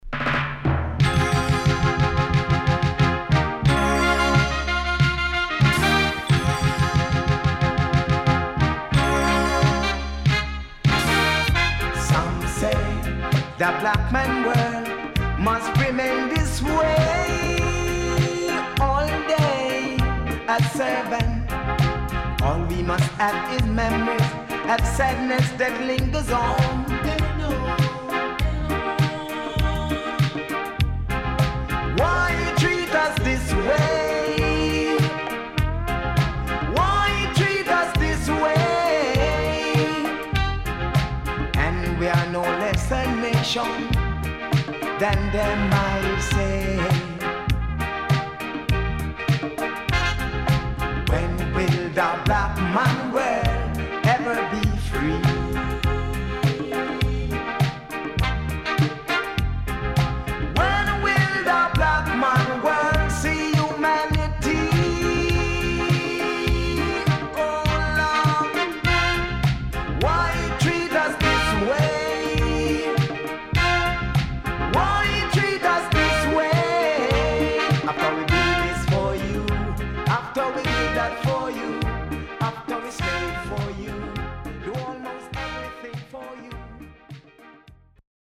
HOME > LP [DANCEHALL]